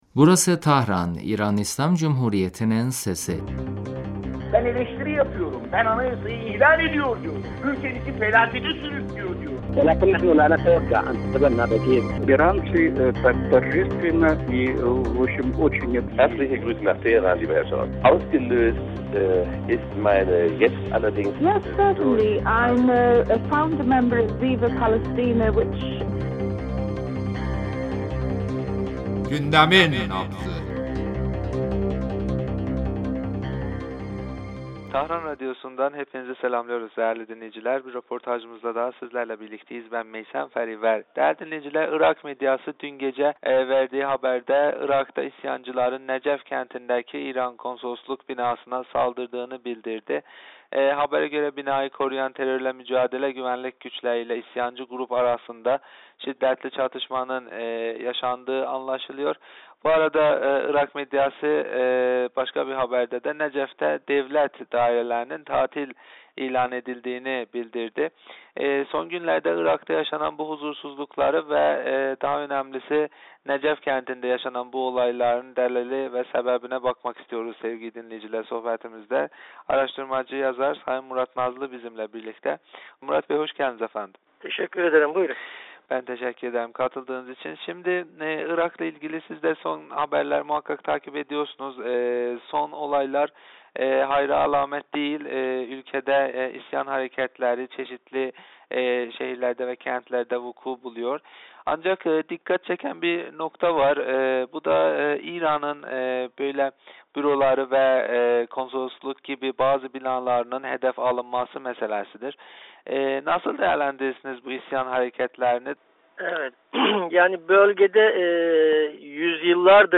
radyomuza verdiği demecinde Irak'taki son huzursuzlukları ve Necef'te İran konsolosluğuna yapılan saldırıyı değerlendirdi...